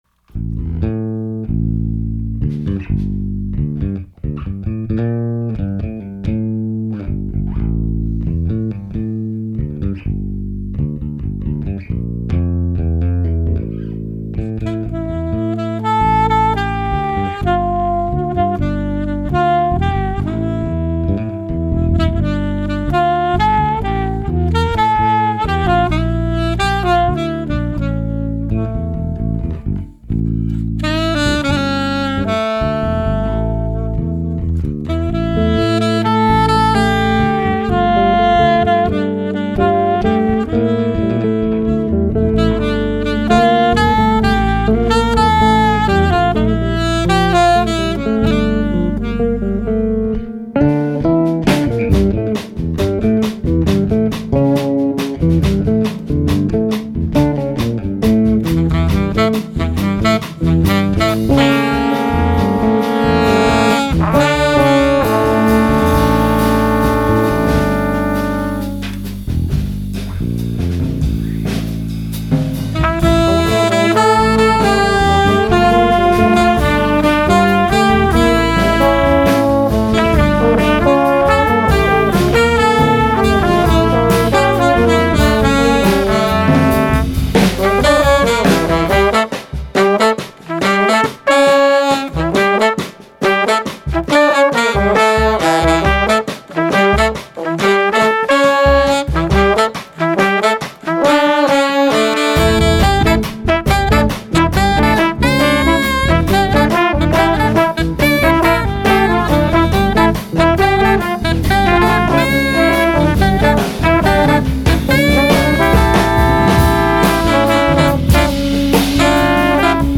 Voicing: Combo